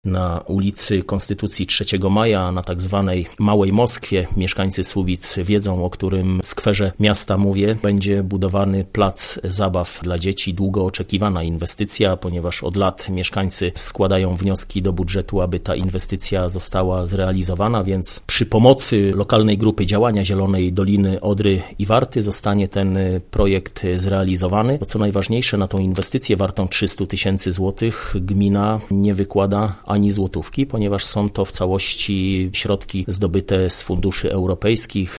– Tym samym dzieci będą miały miejsca, gdzie spokojnie i bezpiecznie będą się bawić, a miasto zyska estetyczne, zielone obszary – informuje Mariusz Olejniczak, burmistrz Słubic.